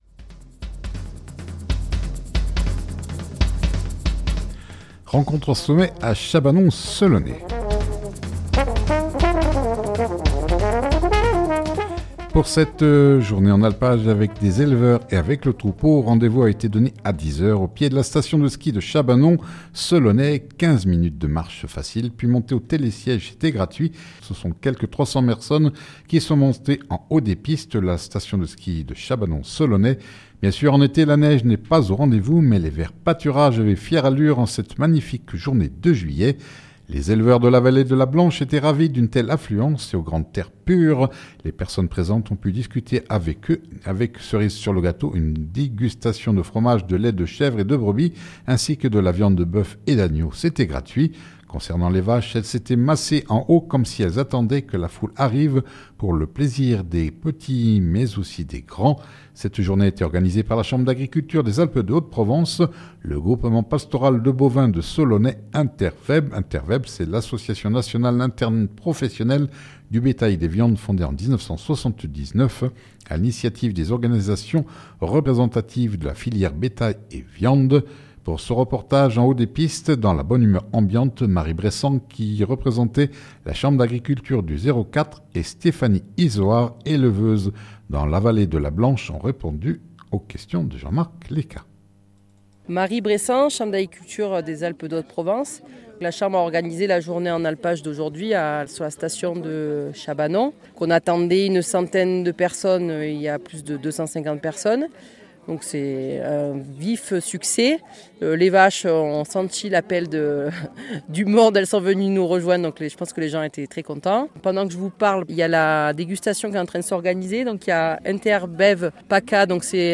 Pour cette journée en alpages avec des éleveurs et avec le troupeau, rendez-vous avait été sonné à 10h au pied de la station de ski de Chabanon 15 min de marche (facile) puis montée en télésiège (gratuit) et ce sont quelque 300 personnes qui sont montées en haut des pistes de la station de ski de Chabanon-Selonnet.